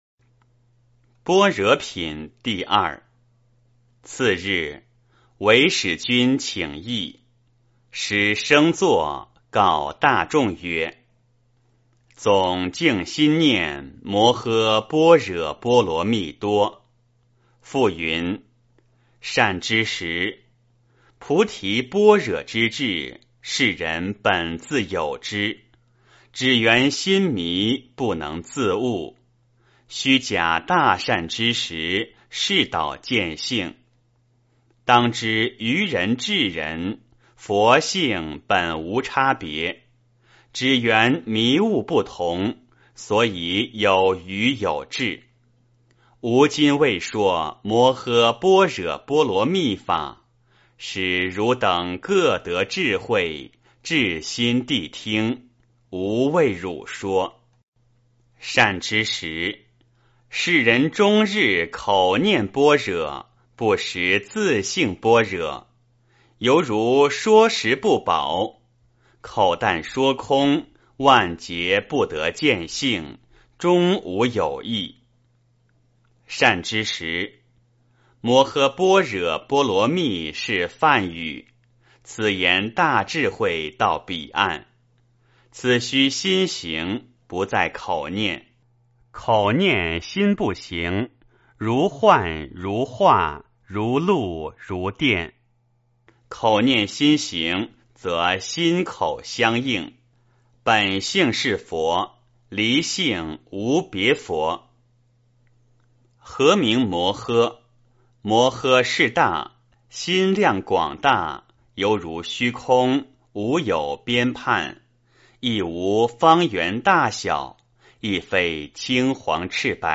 六祖坛经-02般若品（念诵） 诵经 六祖坛经-02般若品（念诵）--未知 点我： 标签: 佛音 诵经 佛教音乐 返回列表 上一篇： 六祖坛经-01行由品（念诵） 下一篇： 六祖坛经-04定慧品（念诵） 相关文章 虚云老和尚讲因果--有声佛书 虚云老和尚讲因果--有声佛书...